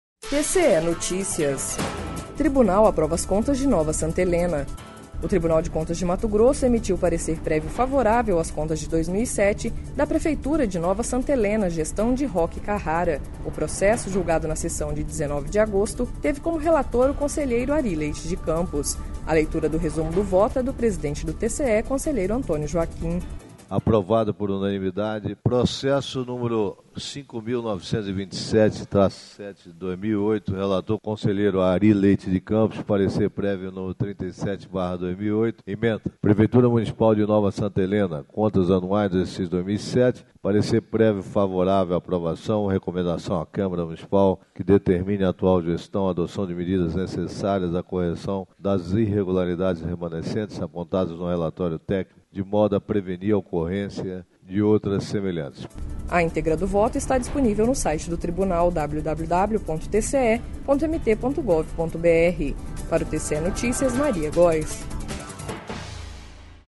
A leitura do resumo do voto é do presidente do TCE, Antonio Joaquim.// Sonora: Antonio Joaquim - presidente do TCE-MT